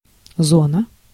Ääntäminen
US : IPA : [ˈprɪzən]